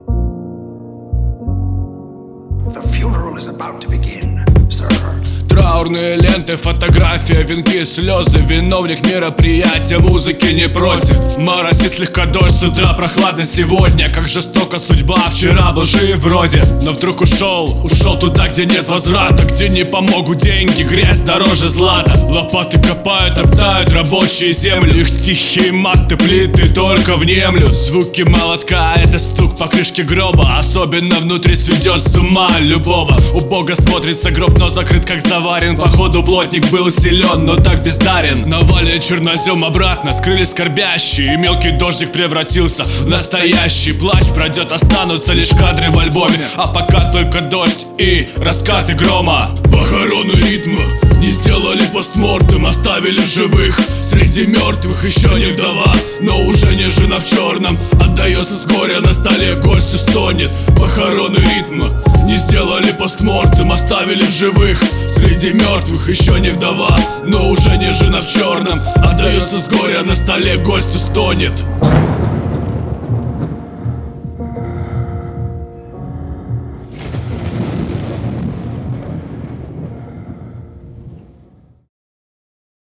Вокал. Демо